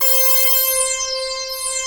BIG LEADC5-L.wav